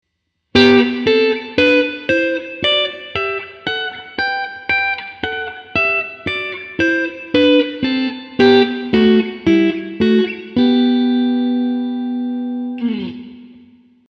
6度音程のダブルストップ2弦、4弦